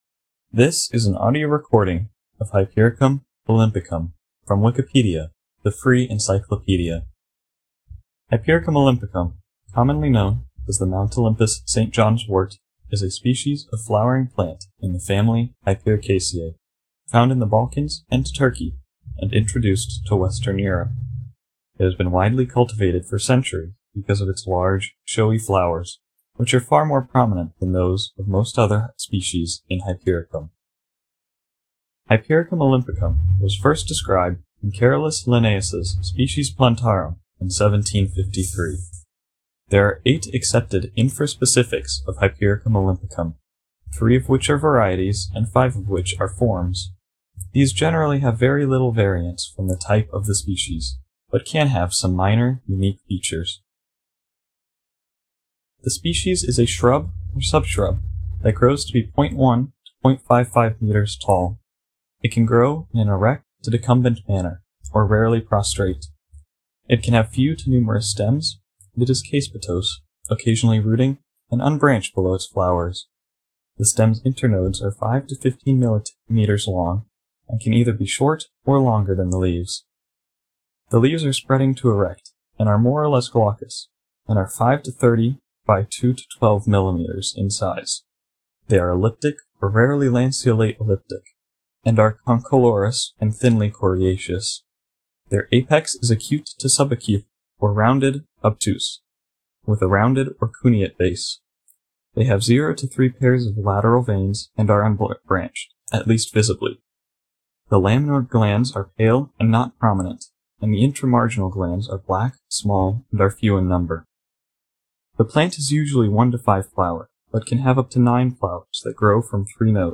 Captions English This is a spoken word version of the Wikipedia article: Hypericum olympicum
En-Hypericum_olympicum-article.ogg